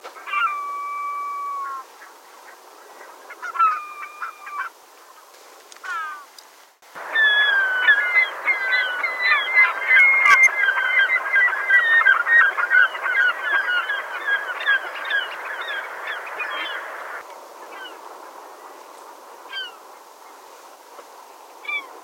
Glaucous gull
The glaucous gull’s call is similar to that of the herring gull but is somewhat lighter in tone.